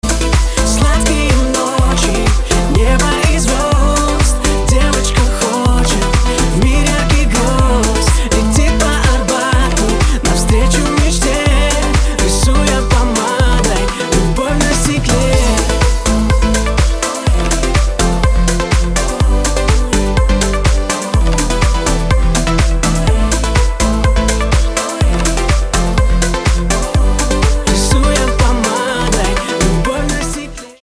• Качество: 320, Stereo
поп
мужской вокал
красивые
dance
спокойные
club
vocal